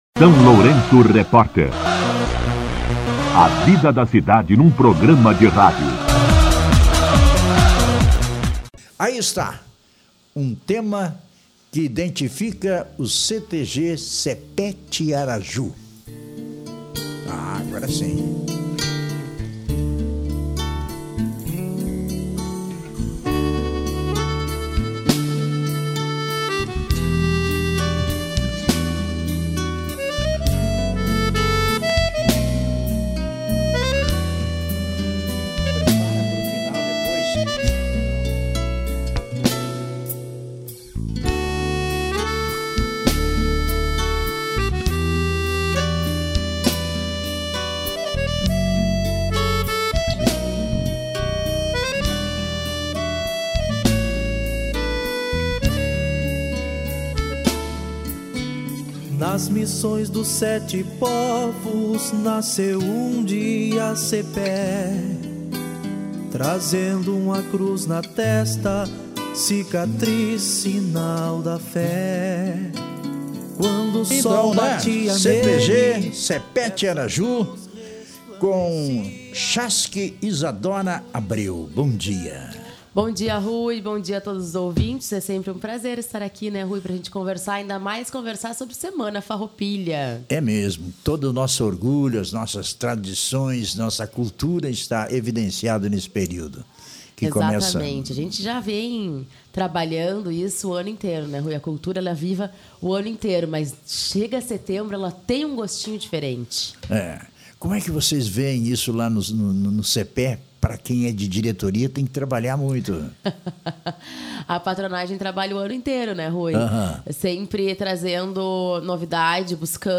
esteve no SLR RÁDIO nesta sexta-feira (12) convidando toda a comunidade a participar.